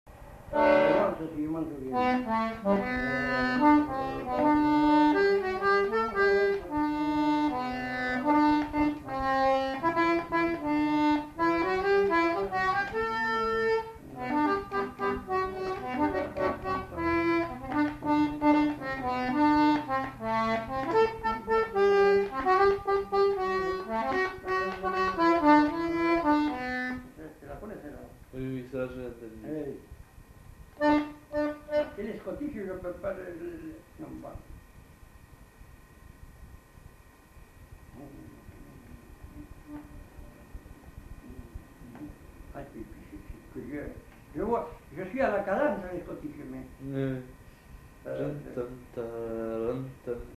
Répertoire d'airs à danser du Marmandais à l'accordéon diatonique
enquêtes sonores
Valse